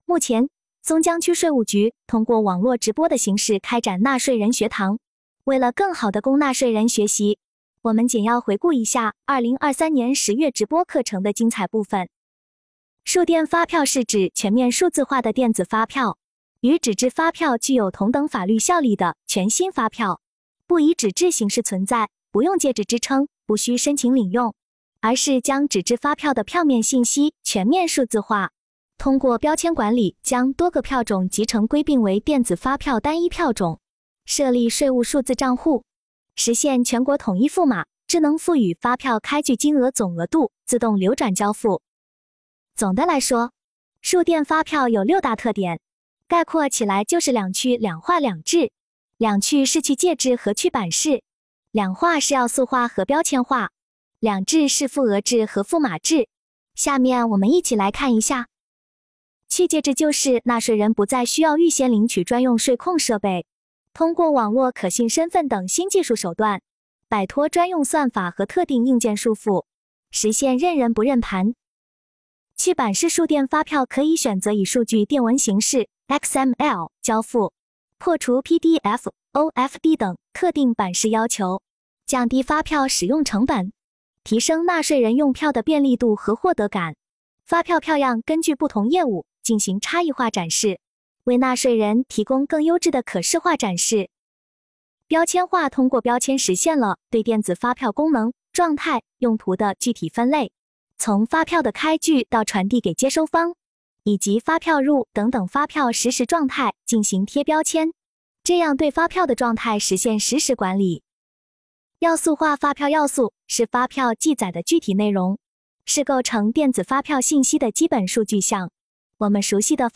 目前，松江区税务局通过网络直播的形式开展了纳税人学堂。为了更好地供纳税人学习，现将10月网络直播课程回放如下，欢迎大家点击收看。
直播课程一